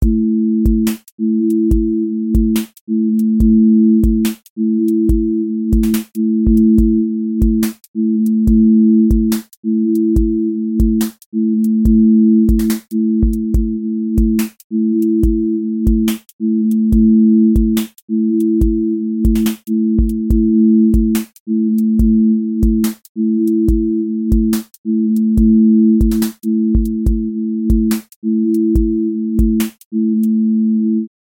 QA Listening Test drill Template: drill_glide
drill glide tension with sliding low end